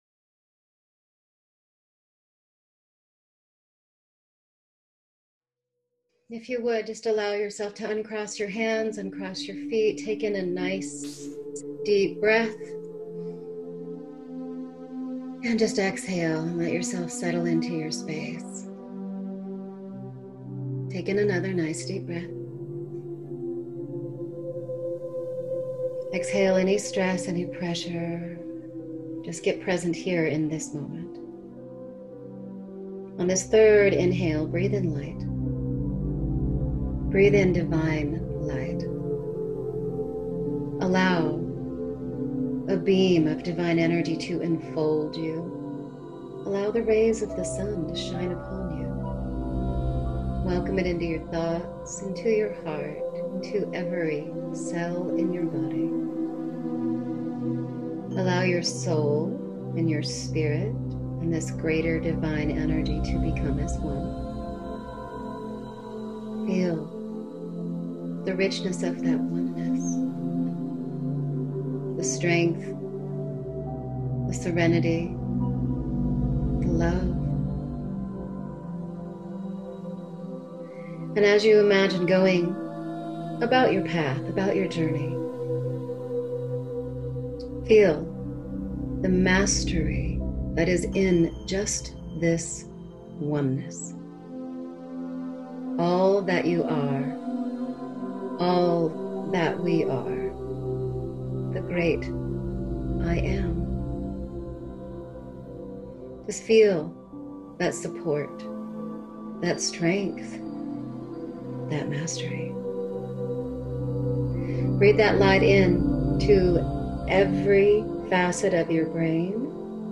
Self Mastery Guided Meditation
Guided Meditation in a soothing voice. Energy healing, energy clearing, Divine Light, meditation, spirituality.